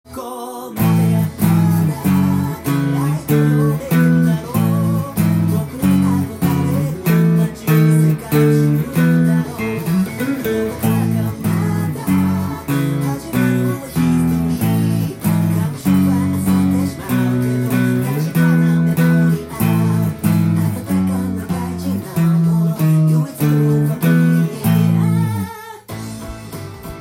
サビがカンタンに弾けるオリジナルtab譜
音源にあわせて譜面通り弾いてみました
カンタンにエレキギターでコードを弾く時は、パワーコードで